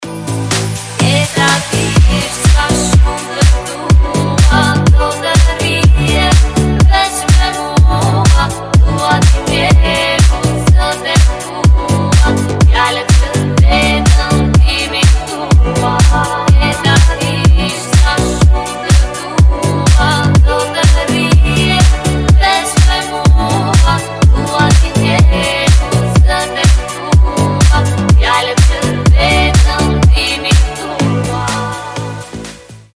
• Качество: 320, Stereo
deep house
dance
Electronic
красивый женский вокал